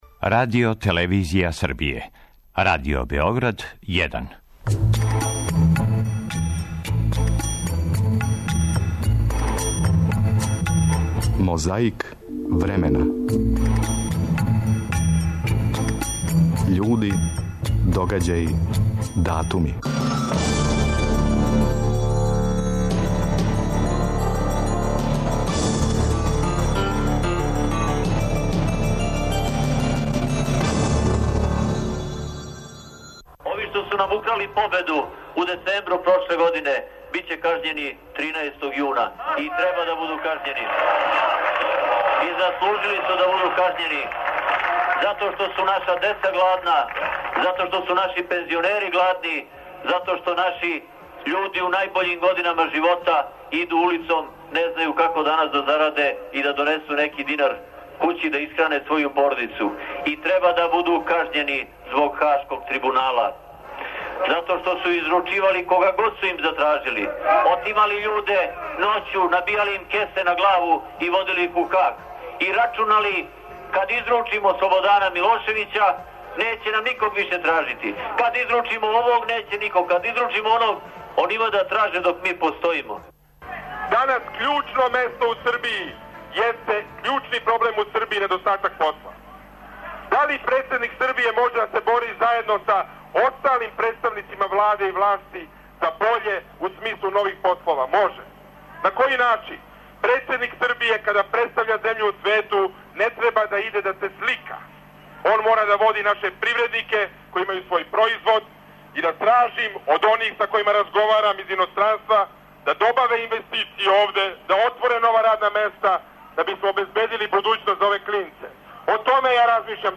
Најновију борбу против заборава почињемо сећањем како су говорили двојица кандидата за председника Србије у предизборној кампањи 2004. године.
Подсећа на прошлост (културну, историјску, политичку, спортску и сваку другу) уз помоћ материјала из Тонског архива, Документације и библиотеке Радио Београда. Свака коцкица Мозаика је један датум из прошлости.